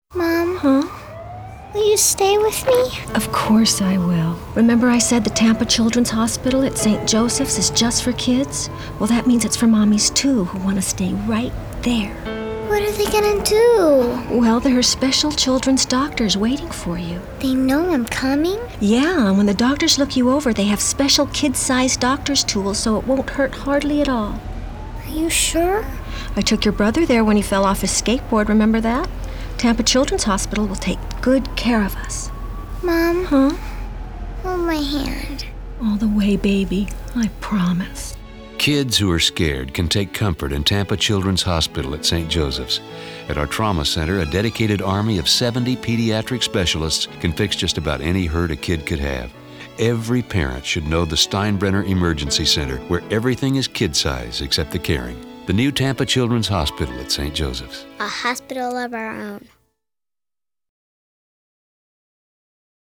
Strategic Radio Commercial Production from Tampa, FL
We audition and cast West Coast voices because LA has the deepest talent pool in the country.